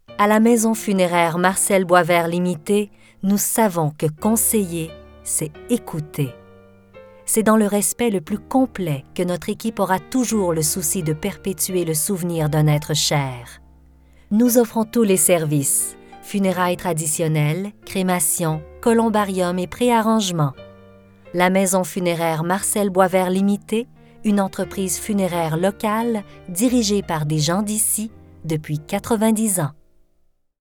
publicité – Ton doux et rassurant
Demo-ton-doux-et-rassurant-pub-soft-sell.mp3